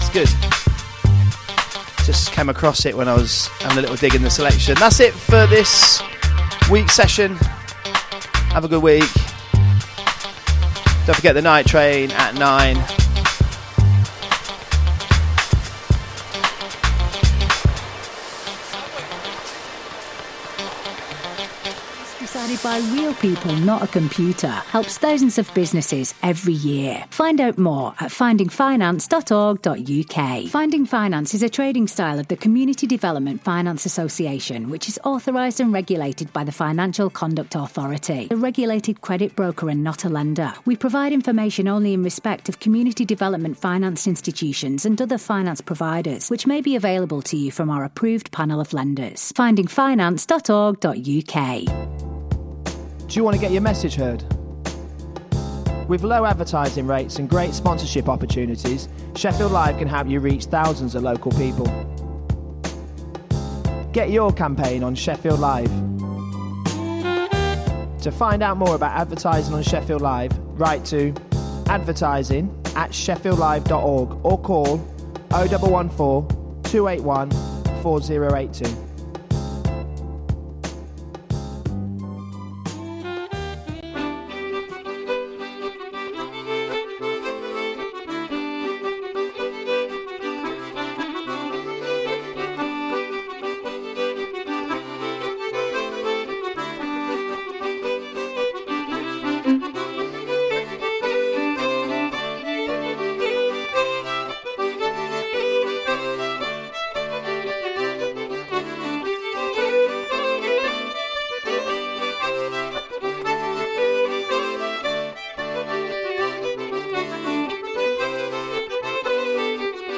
The very best new upfront music ahead of release dates & classic old tunes ranging round dub, chill, breaks, jungle, dnb, techno & anything else